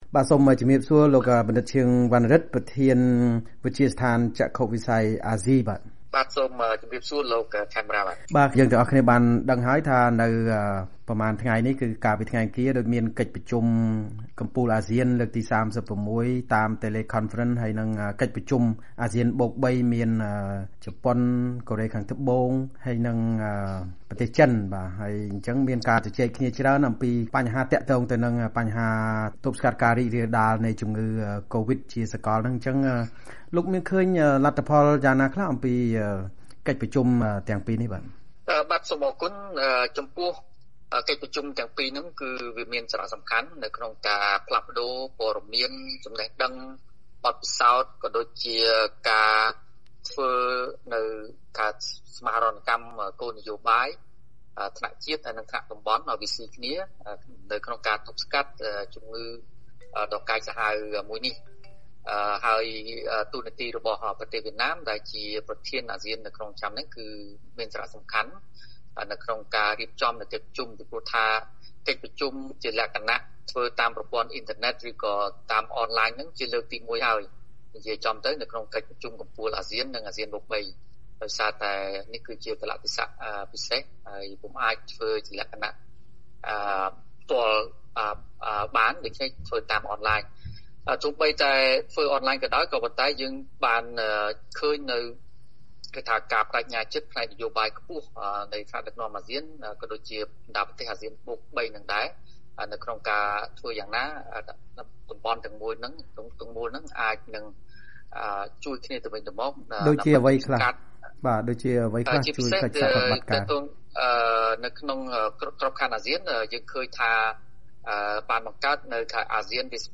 បទសម្ភាសន៍ VOA៖ អ្នកវិភាគថា មេដឹកនាំអាស៊ានតាំងចិត្តខ្ពស់ដើម្បីប្រឆាំងនឹងកូវីដ១៩ តែហានិភ័យនៅខ្ពស់